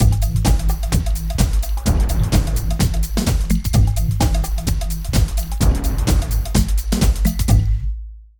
50 LOOP   -R.wav